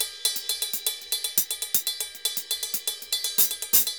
Ride_Salsa 120_2.wav